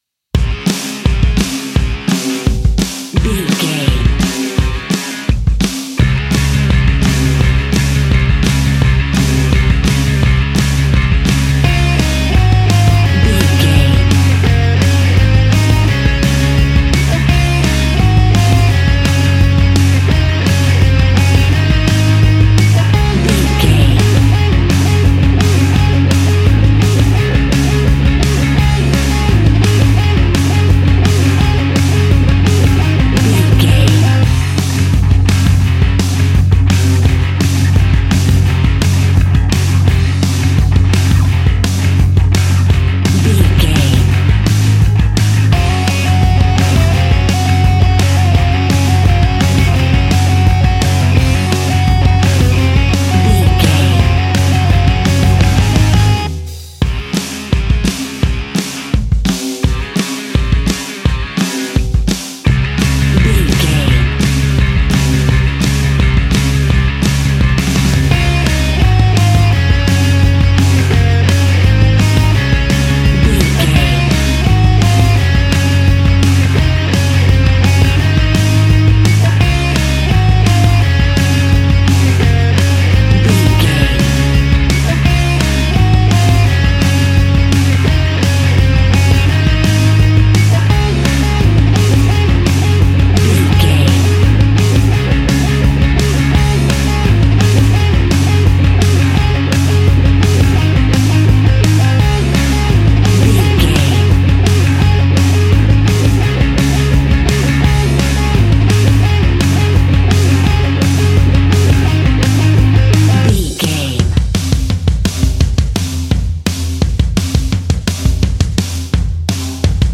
Uplifting
Ionian/Major
D♭
hard rock
distortion
punk metal
instrumentals
Rock Bass
heavy drums
distorted guitars
hammond organ